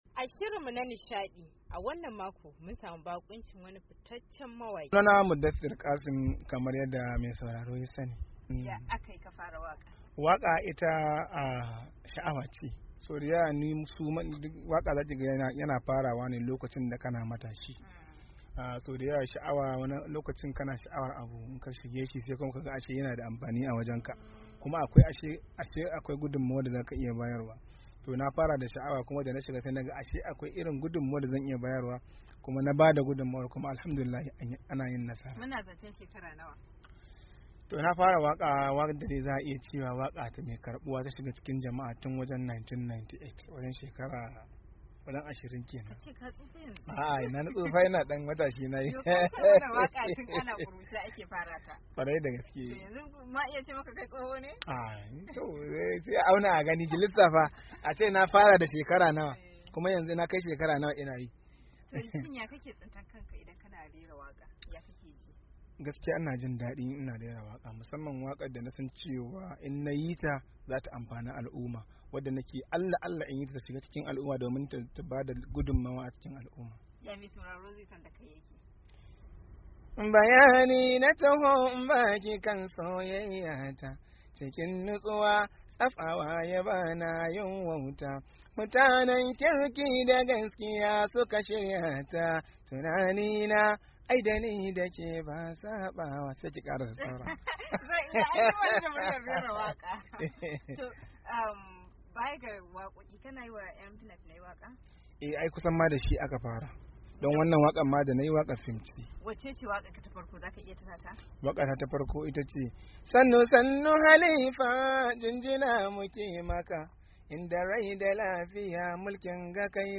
Hira Da Mawaki